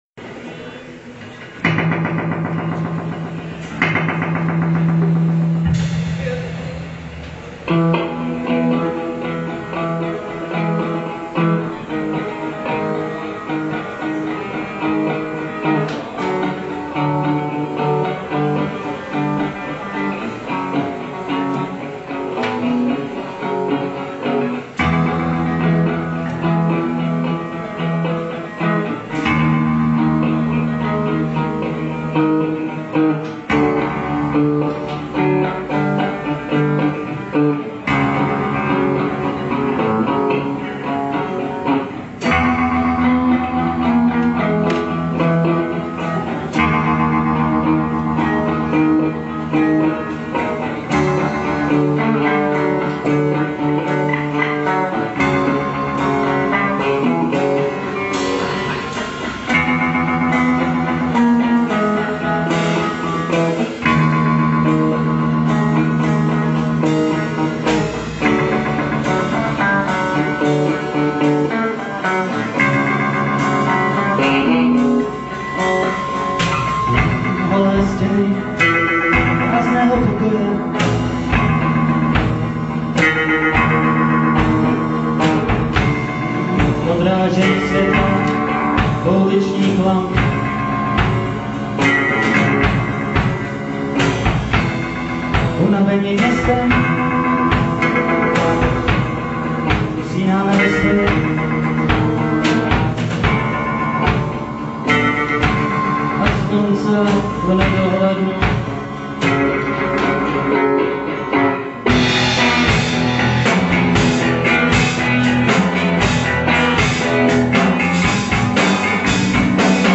Jedna píseň (mobil. záznam) z koncertu (za 29. vteřinu se stydím big_smile):
...ta nahrávka z mobilu-kvalita na prd. sad Podle toho vůbec nedokážu posoudit kvalitu muziky,...vyzní to jen jako nějakej "šum a rambajz". sad Takový nahrávky bych asi k publikaci nepoužil,...to nemá smysl. roll
nahodou ta 29. tomu dava takovy Portishead feel big_smile